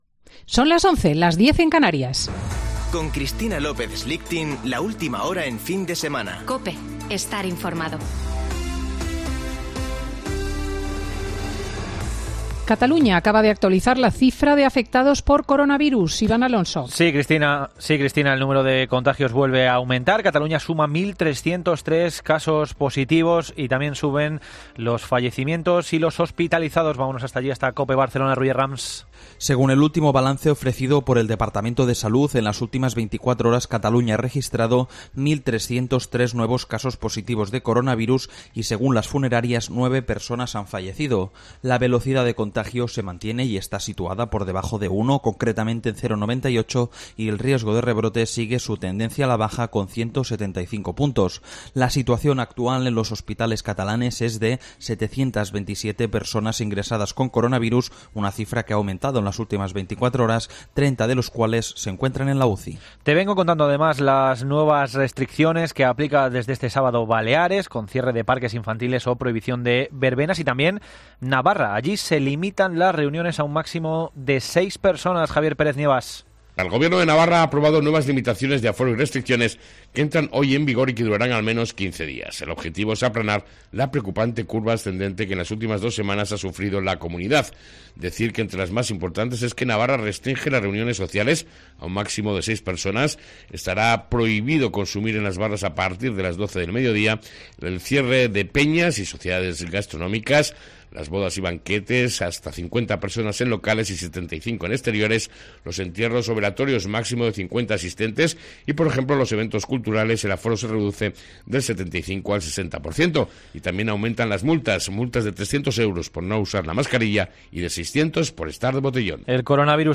Boletín de noticias de COPE del 12 de septiembre de 2020 a las 11.00 horas